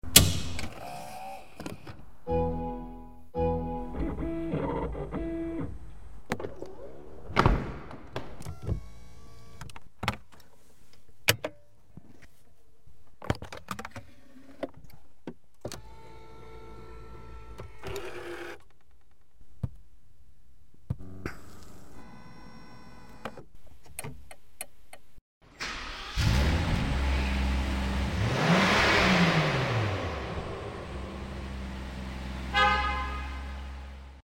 ASMR Rolls Royce Cullinan 2025 sound effects free download